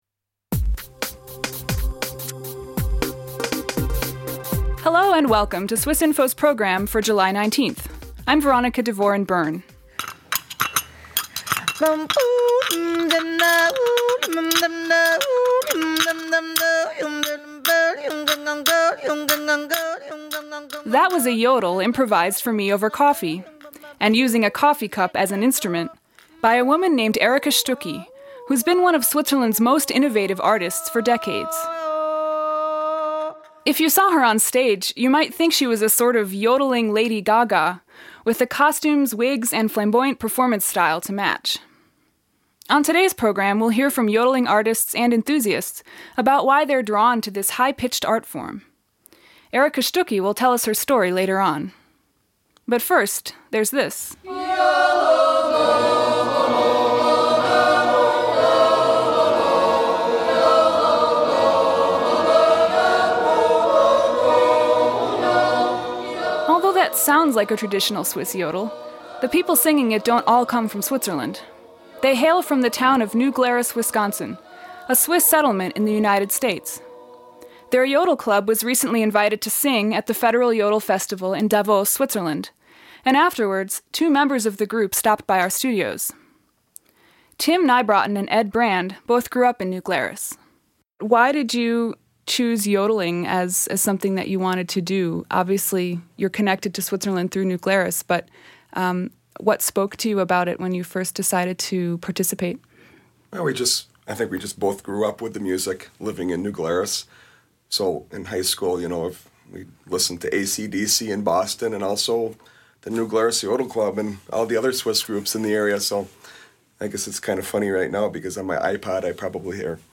The roots of yodeling go far beyond Switzerland and have spawned totally new musical styles. Artists and enthusiasts from around the world go beyond the stereotypes to explain why they yodel.